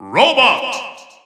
The announcer saying R.O.B.'s name in Japanese and Chinese releases of Super Smash Bros. 4 and Super Smash Bros. Ultimate.
R.O.B._Japanese_Announcer_SSB4-SSBU.wav